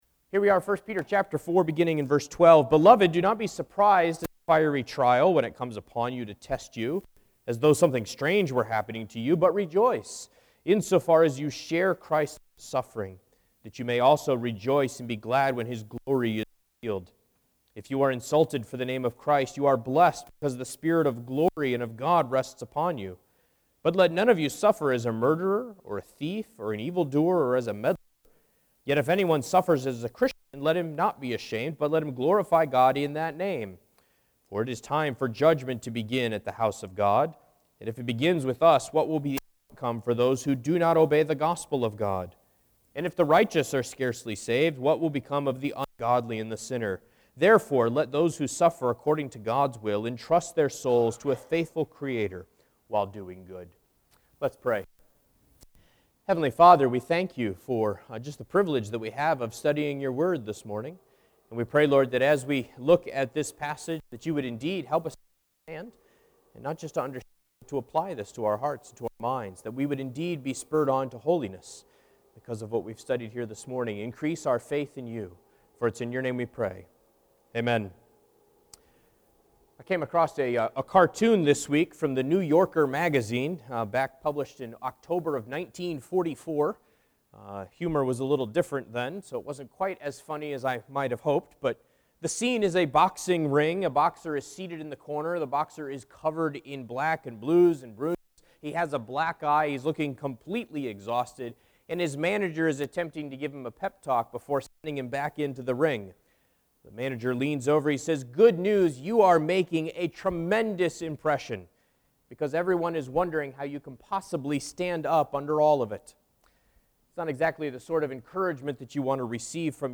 1 Peter 4:12-19 Service Type: Sunday Morning %todo_render% « Golden Chain of Salvation